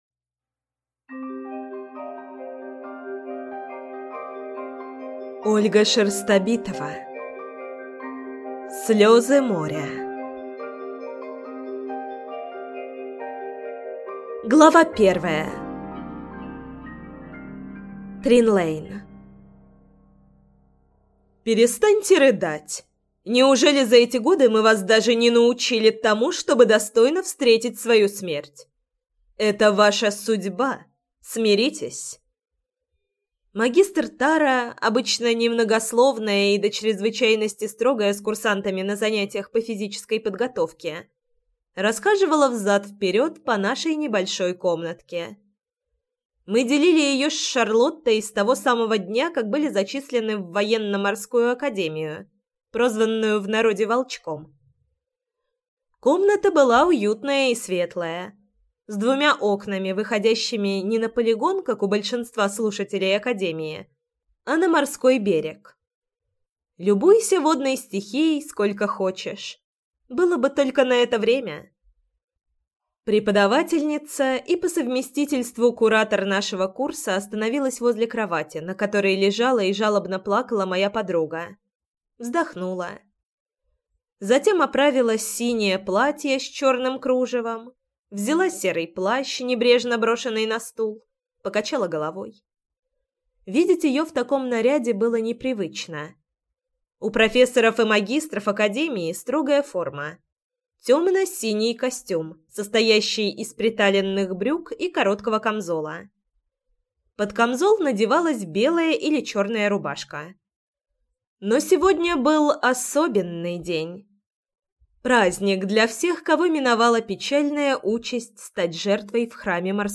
Аудиокнига Слезы Моря - купить, скачать и слушать онлайн | КнигоПоиск